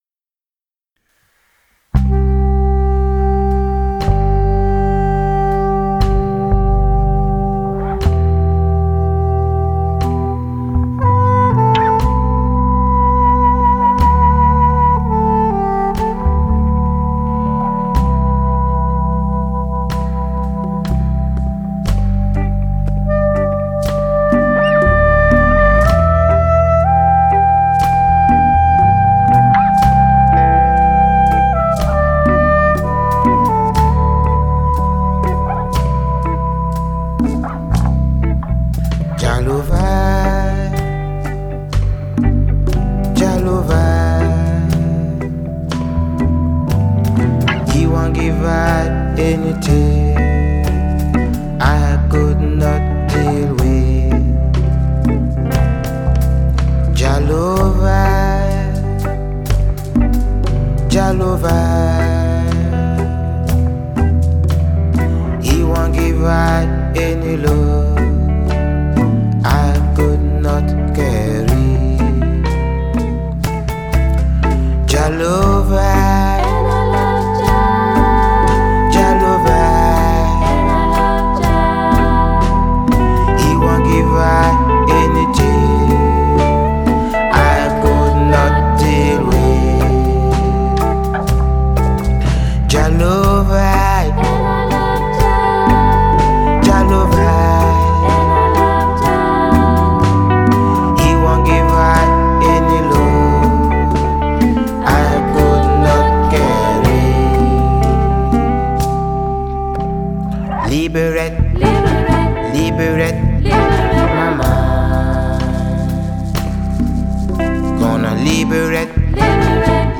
Reggae
Keyboard
Sax and Flute
Backing Vocals
Bass